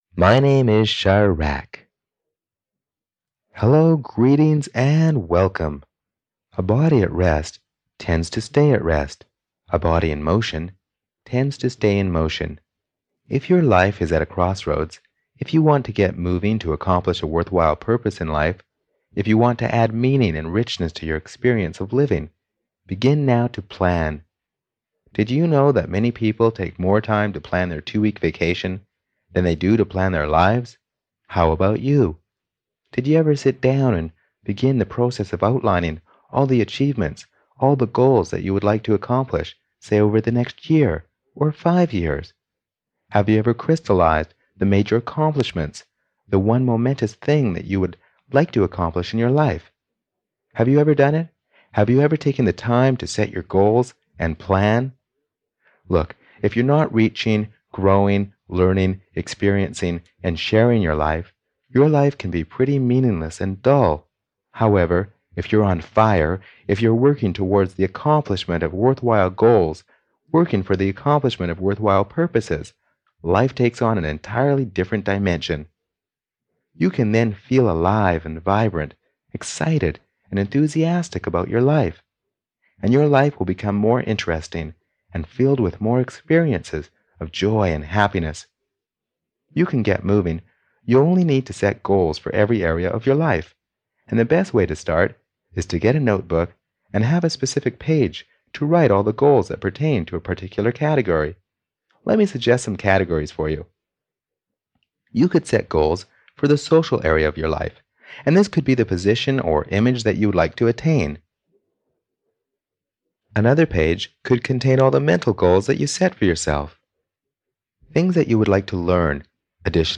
Goal Setting (EN) audiokniha
Ukázka z knihy